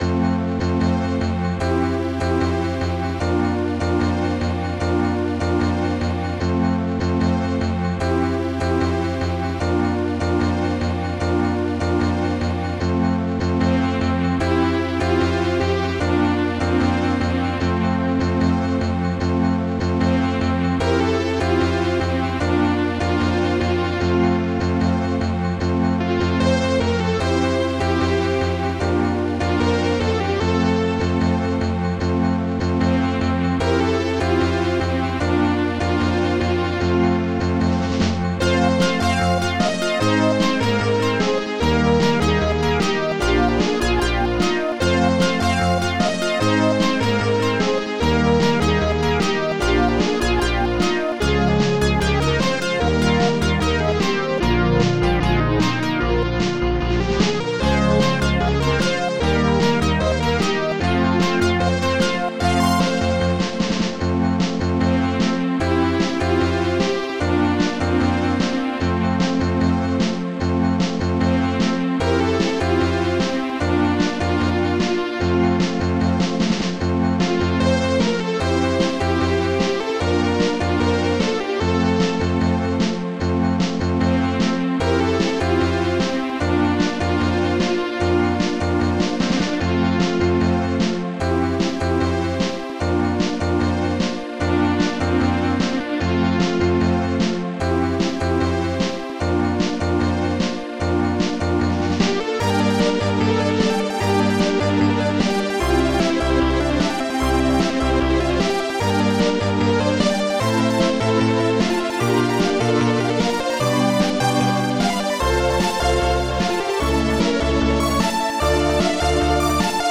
Digital Symphony Module  |  1994-05-16  |  61KB  |  2 channels  |  44,100 sample rate  |  4 minutes, 28 seconds
st-89:snare2